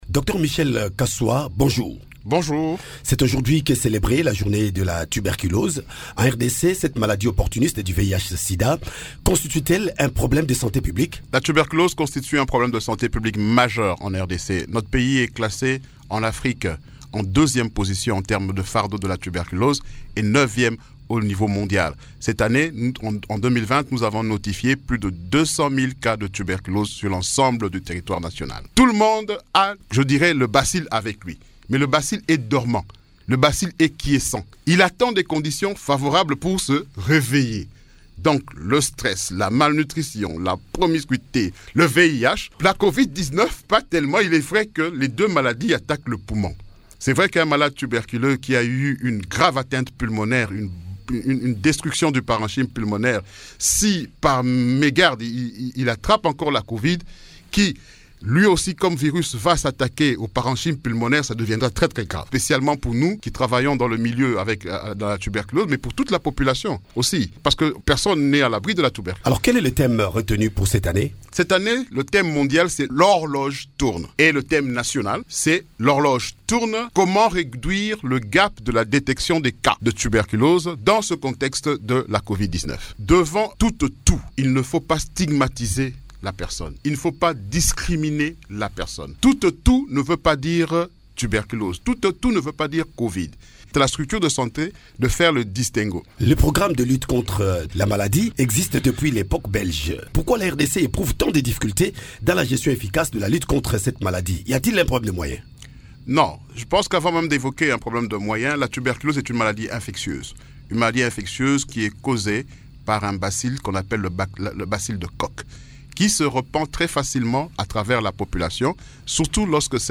« La tuberculose constitue un problème de santé publique majeure en RDC », a-t-il dit, lors d’une une interview accordée à Radio Okapi à l’occasion de la journée internationale de lutte contre la tuberculose célébrée le 24 mars.